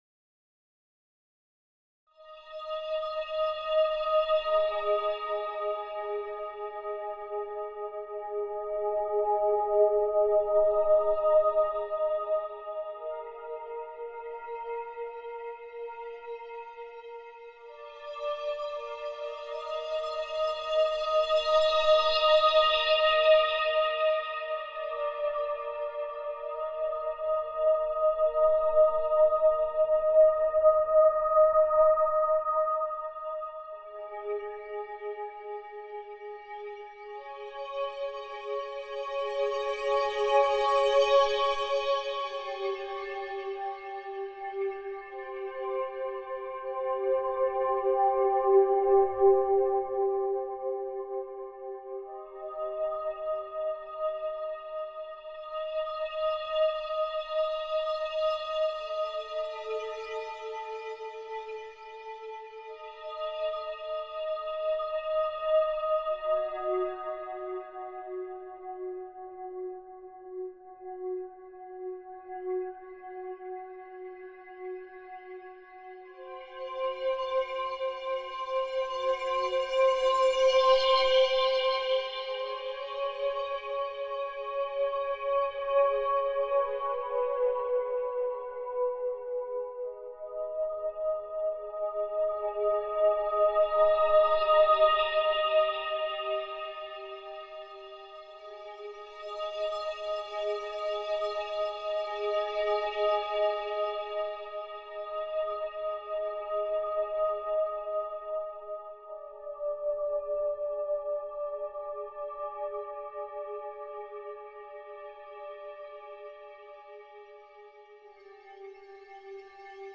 Non c’è ritmo. Non c’è tempo. C’è solo un campo sonoro che respira lentamente — come il pensiero prima di farsi parola, come la presenza prima di farsi gesto.
Atmosfera L’atmosfera è rarefatta, ma non fredda. Le onde sonore si muovono come veli di luce in una stanza immobile, sfiorando appena lo spazio interiore di chi ascolta.
Tensione e rilascio Il brano non ha una “narrativa” in senso tradizionale, eppure crea un movimento emotivo lento, circolare, come una spirale che respira: Non ci sono picchi, né cadute.
È una musica che non pretende: si offre come presenza silenziosa, quasi contemplativa.